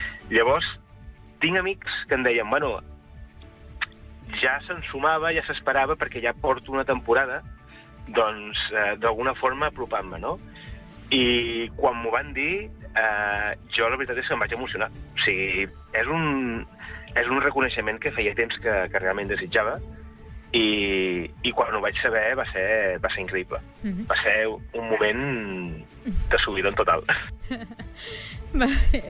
Entrevistes SupermatíSupermatí
En una entrevista concedida al Supermatí